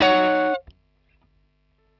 Ebm7_20.wav